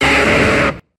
Grito de Charmeleon.ogg
Grito_de_Charmeleon.ogg.mp3